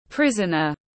Tù nhân tiếng anh gọi là prisoner, phiên âm tiếng anh đọc là /ˈprɪz.ən.ər/.
Prisoner /ˈprɪz.ən.ər/